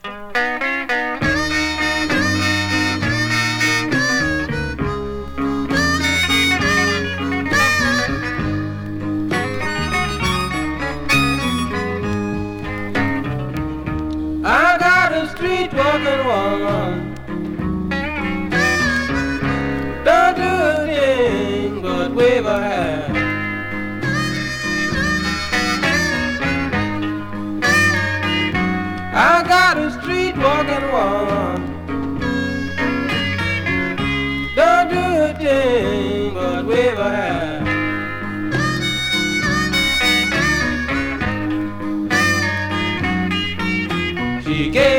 Zydeco　UK　12inchレコード　33rpm　Mono